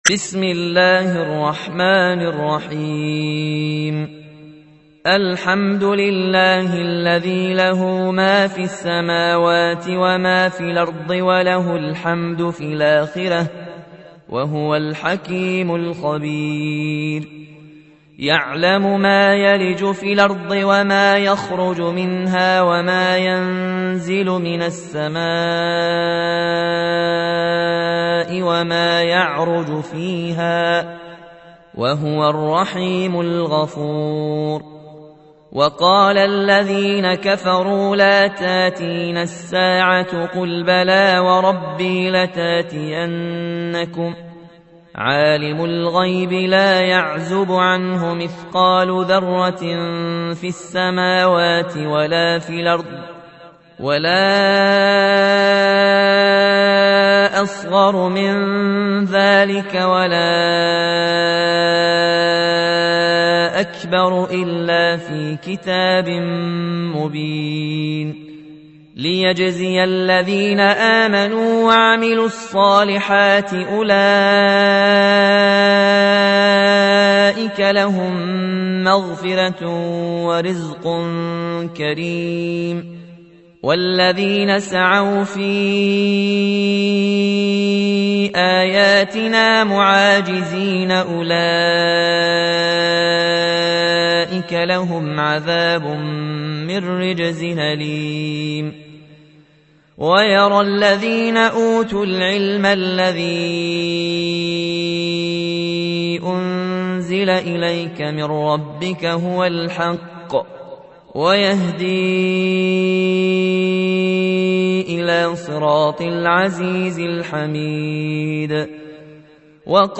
تحميل : 34. سورة سبأ / القارئ ياسين الجزائري / القرآن الكريم / موقع يا حسين